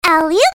scout-jump1.mp3